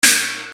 SP CHINA.wav